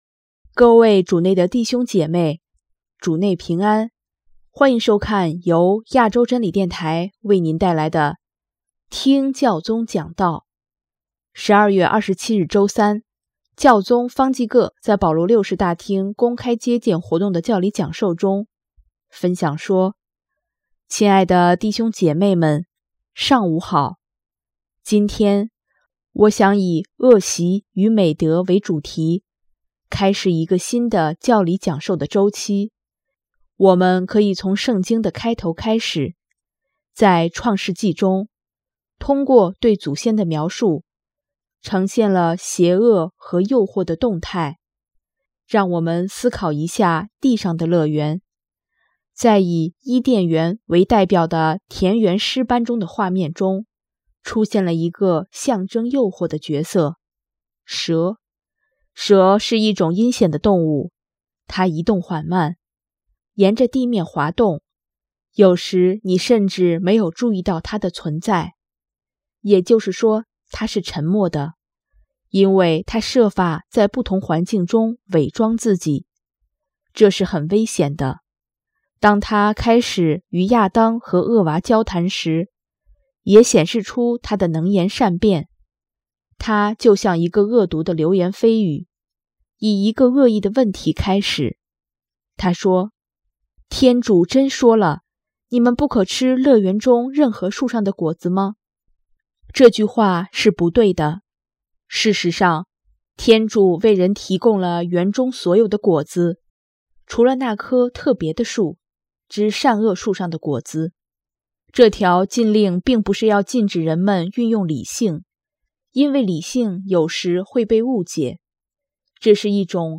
12月27日周三，教宗方济各在保禄六世大厅公开接见活动的教理讲授中，分享说：